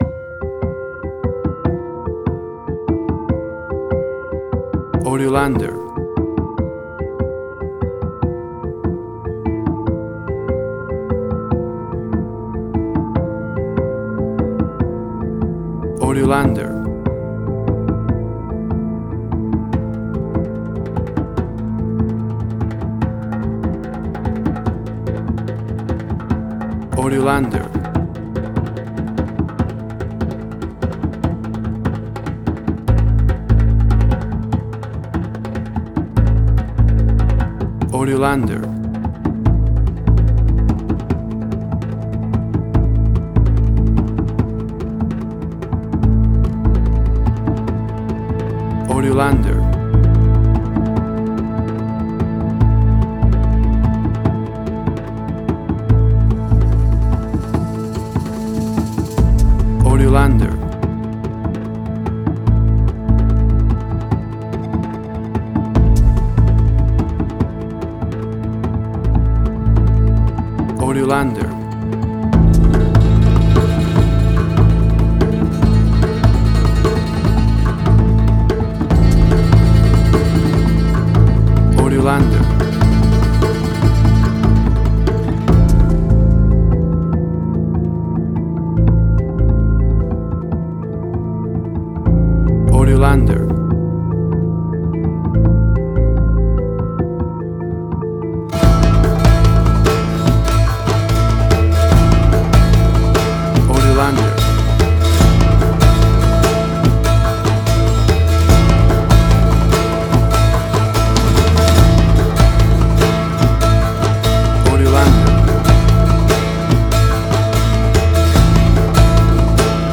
Suspense, Drama, Quirky, Emotional.
Tempo (BPM): 146